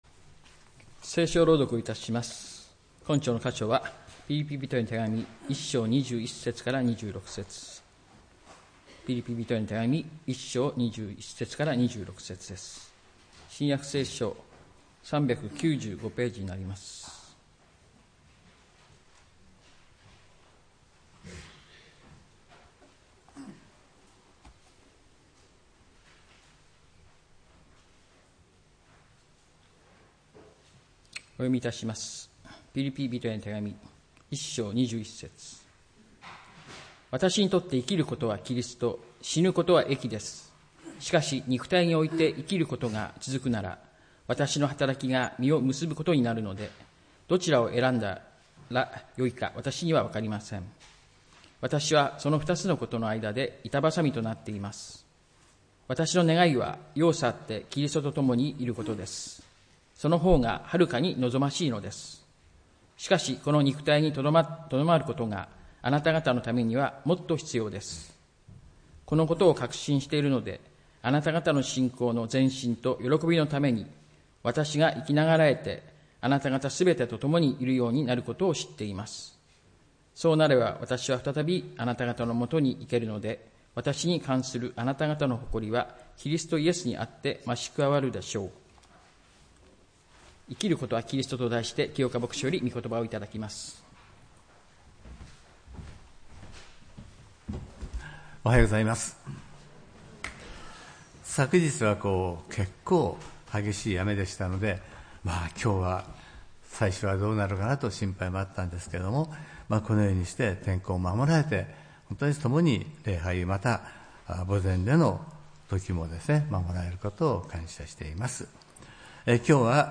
礼拝メッセージ「生きることはキリスト」(11月３日）